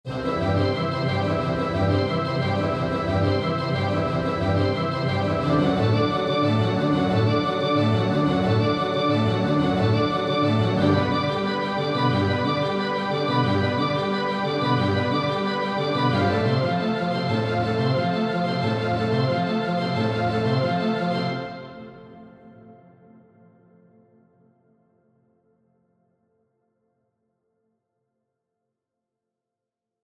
Den internen Faltungshall habe ich dafür ausgeschaltet, da sich sonst zu viele räumliche Anteile überlagern würden.
So hört es sich in der Kirche Saint Ouen in Rouen an (Altiverb XL, Surround-Preset mit 12,5 sek. Nachhallzeit).